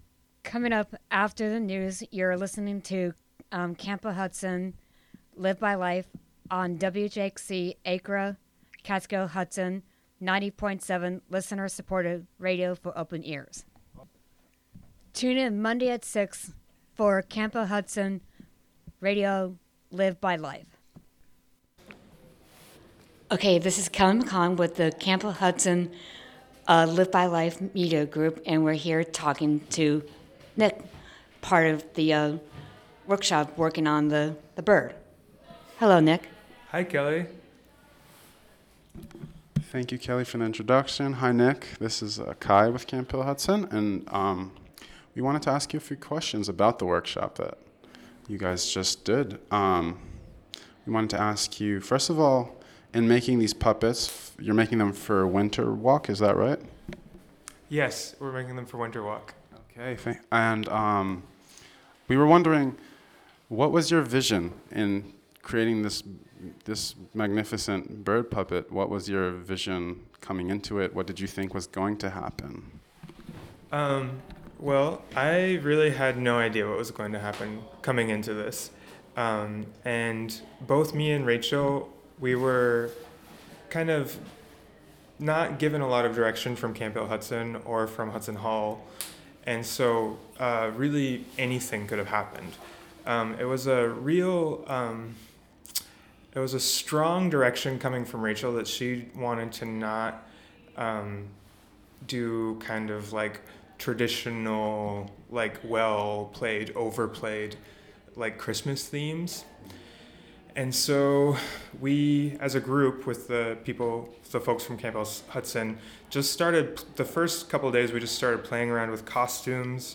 7pm The Camphill Hudson Media Group interviews artist...